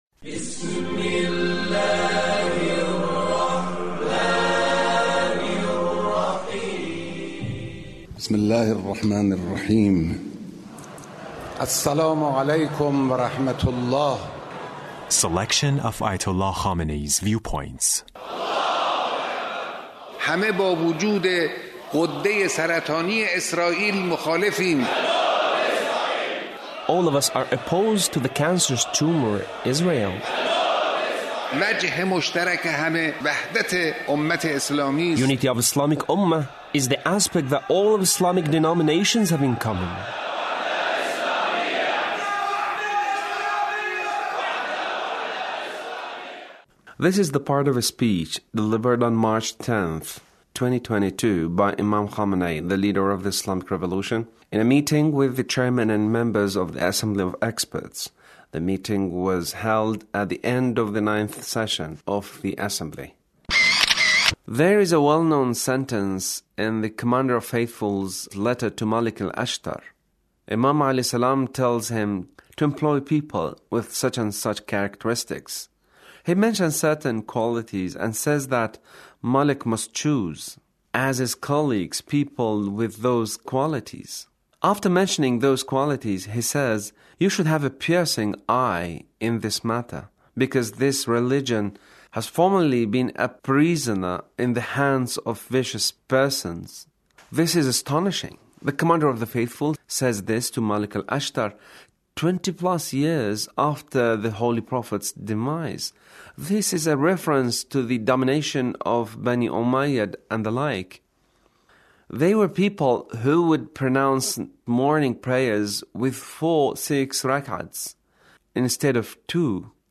Leader's speech (1367)